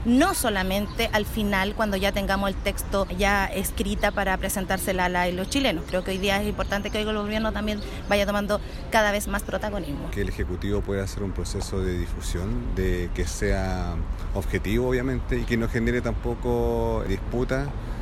A esto se refirieron la constituyente del Partido Comunista, Karen Araya, y su par de RD, Julio Ñanco.